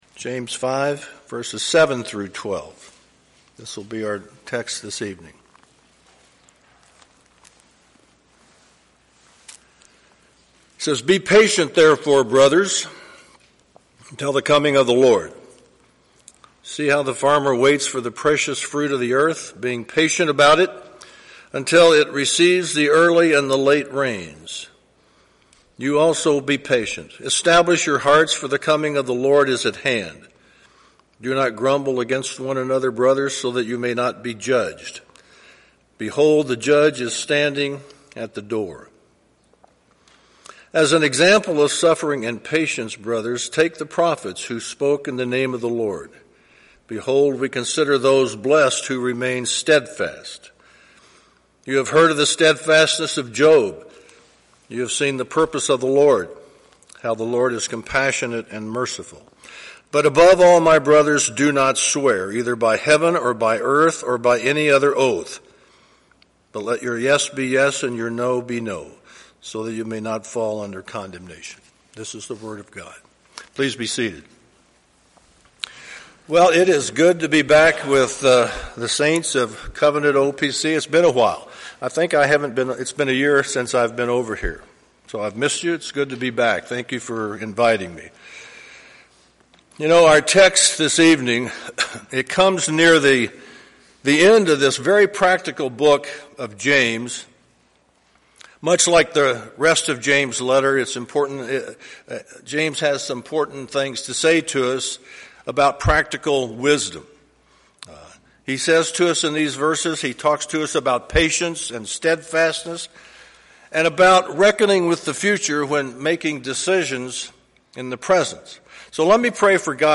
(Guest preacher)